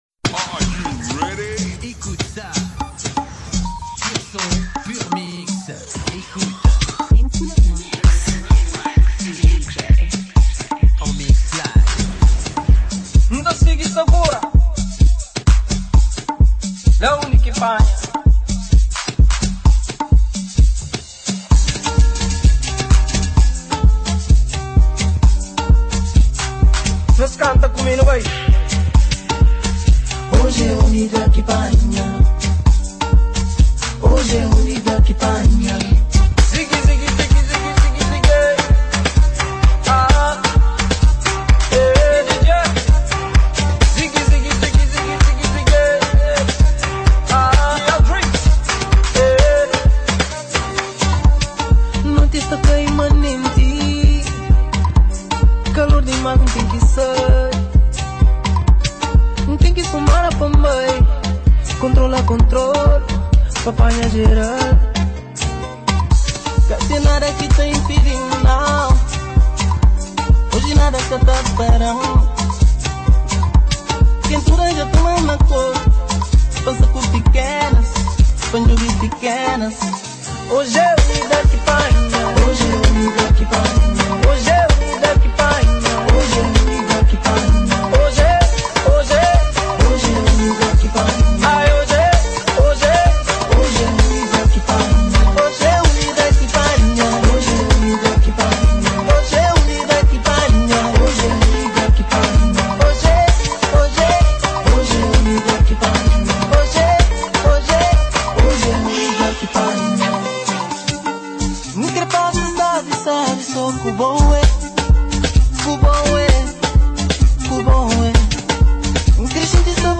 Genre: MIXTAPE.